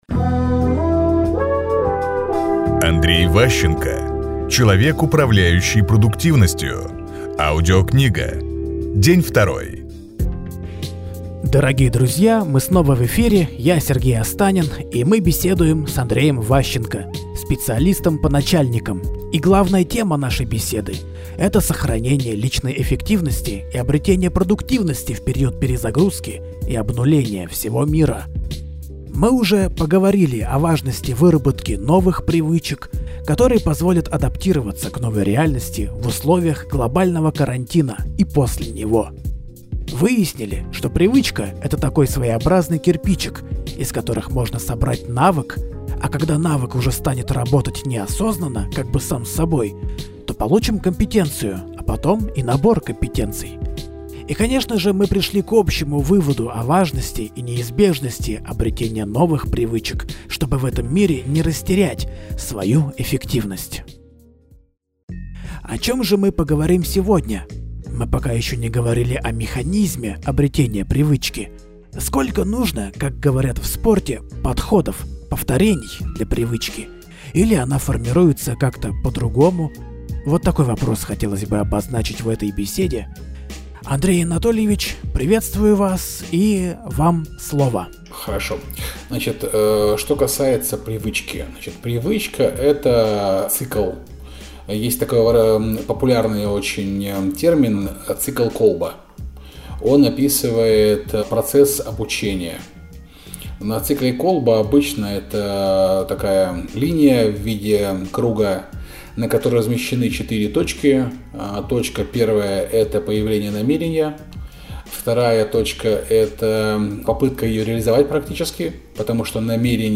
Аудиокнига Человек, управляющий продуктивностью. Часть 2 | Библиотека аудиокниг